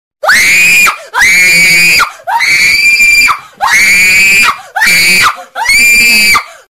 Звуки для пробуждения
Дикий крик женщины чтобы проснуться